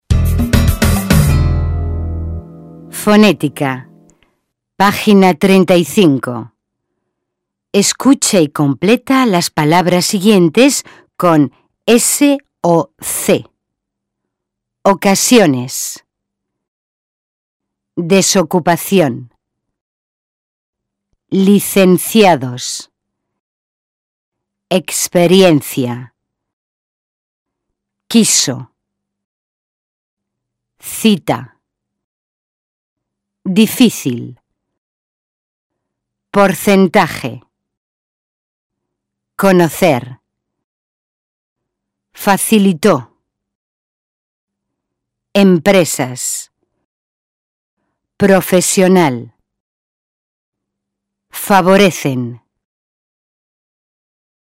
Entrevista de trabajo